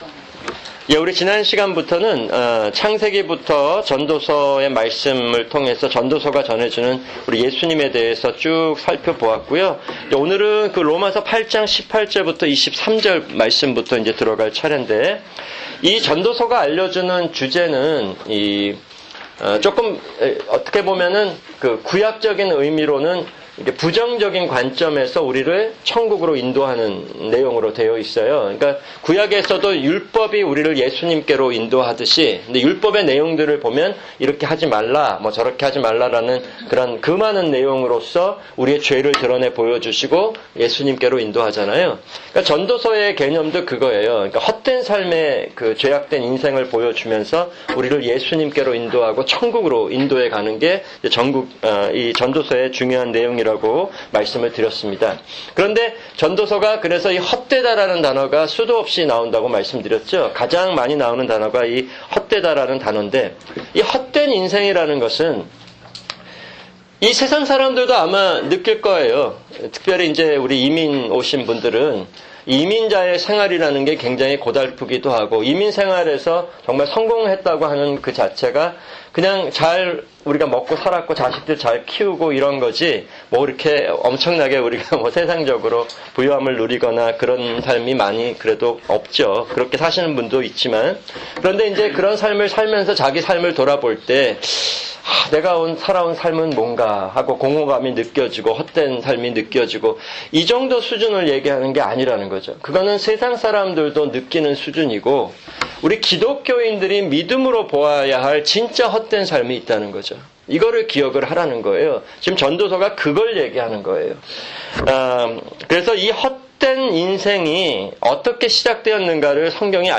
[주일 성경공부] 성경개관- 전도서(7)